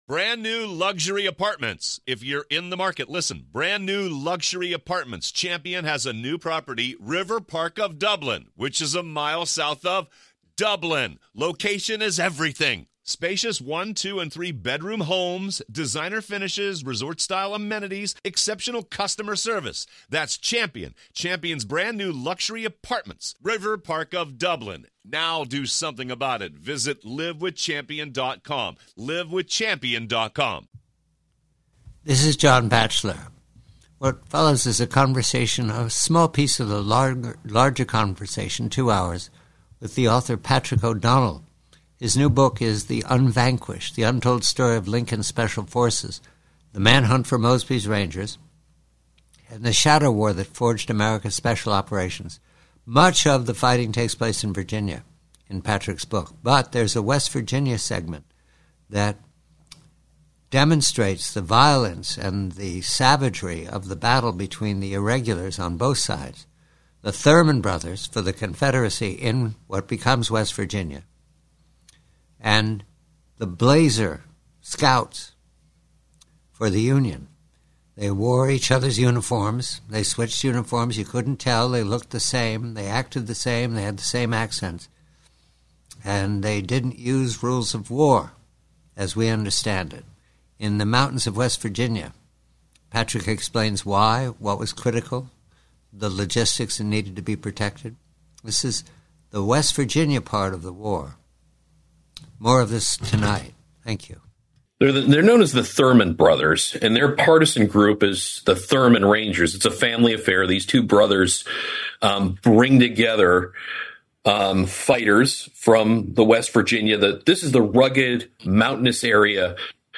PREVIEW: West Virginia: Conversation